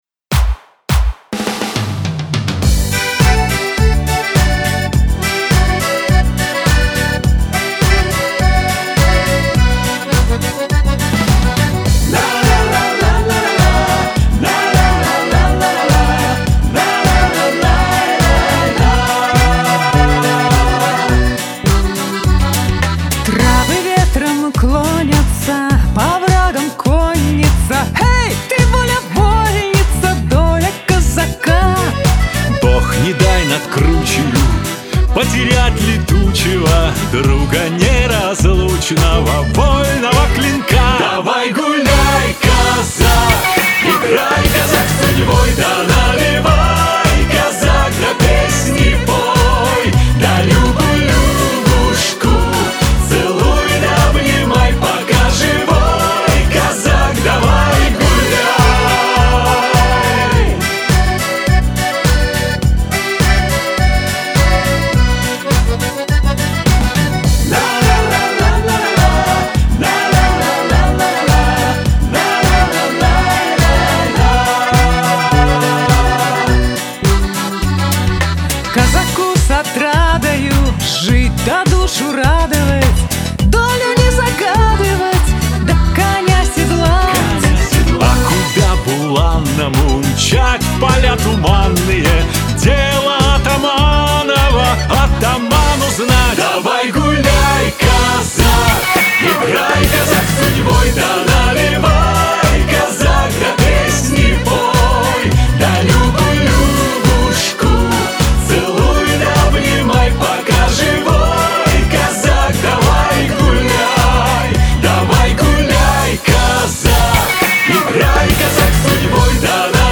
обладатель бархатистого баритона